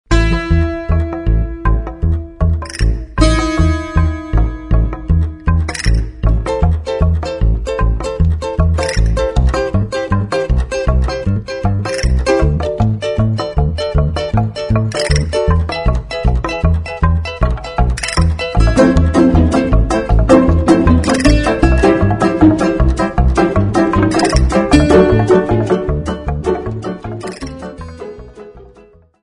Cine y Televisión